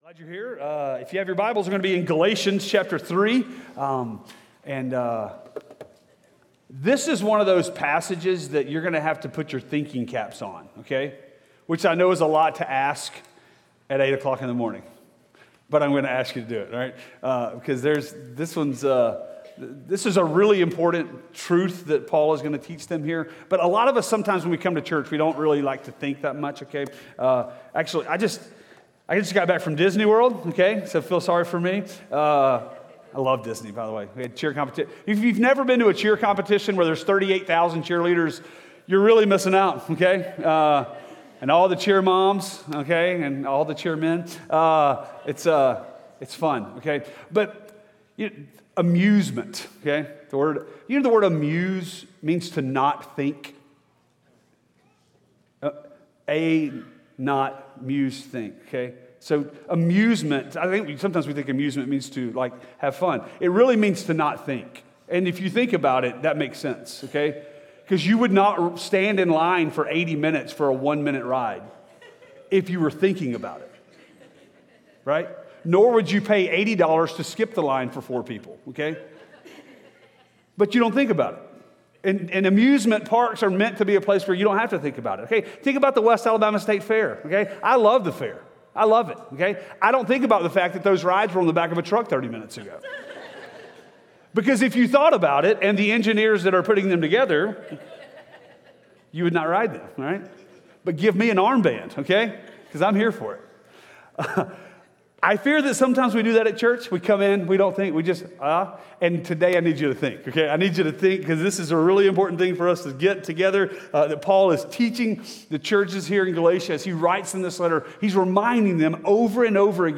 Sermons Galatians What The Law Could Not Do, God Did! Galatians 3:15-29